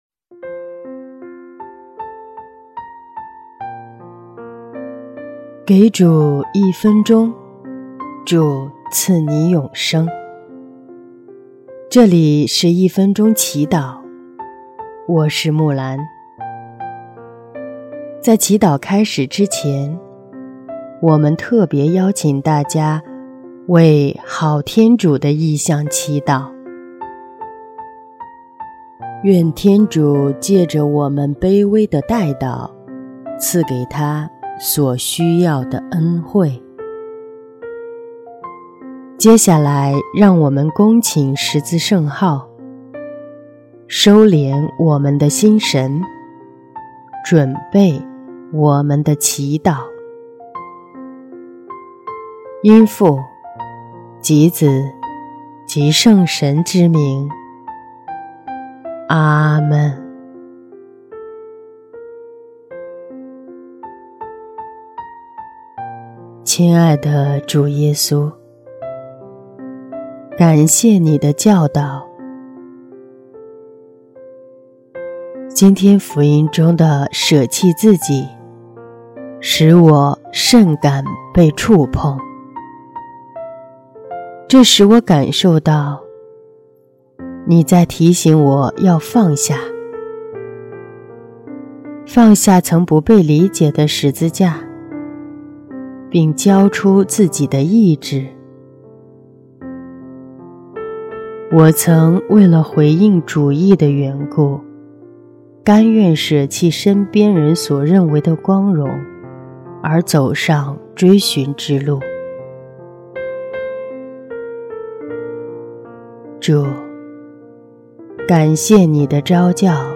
（好天主） 音乐： 第二届华语圣歌大赛参赛歌曲《你来跟随我》